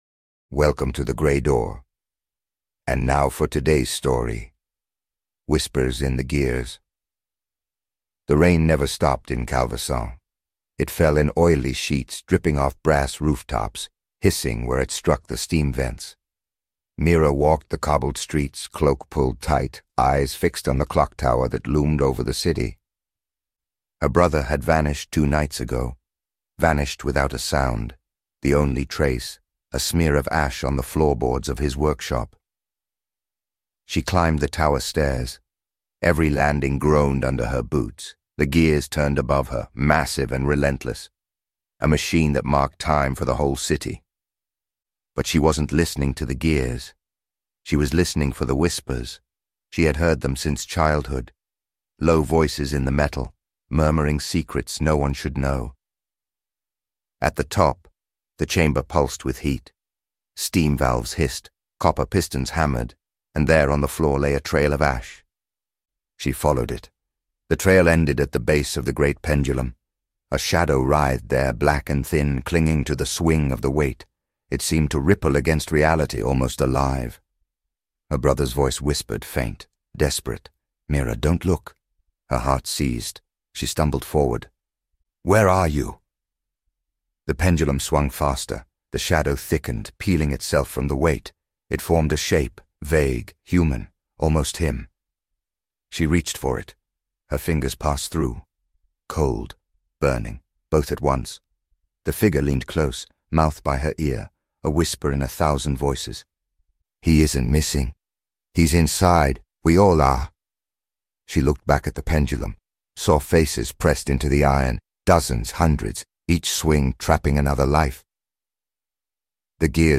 The Gray Door is a storytelling podcast